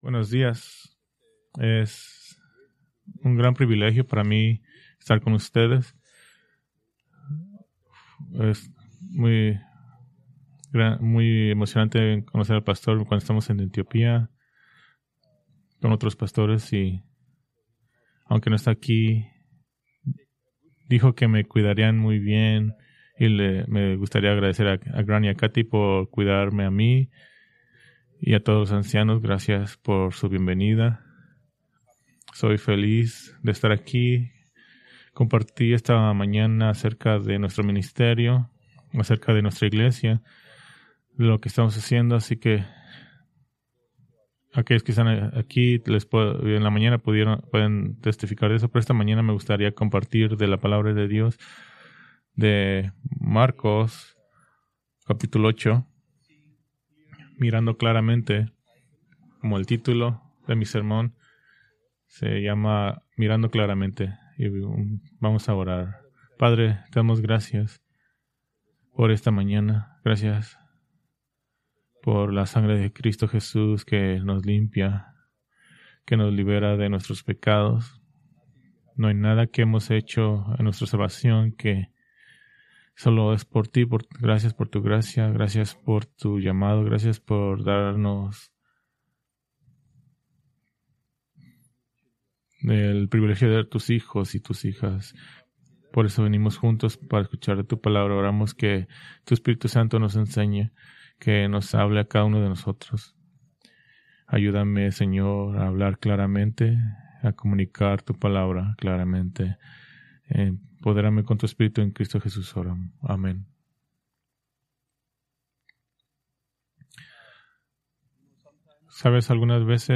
Preached March 8, 2026 from Marcos 8:14-38